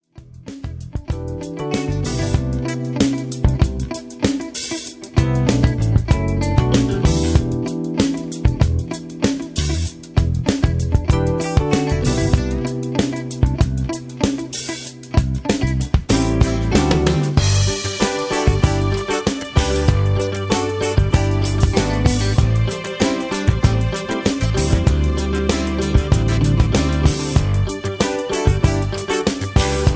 (Version-2, Key-G) Karaoke MP3 Backing Tracks
Just Plain & Simply "GREAT MUSIC" (No Lyrics).